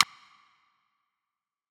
Rimshot.wav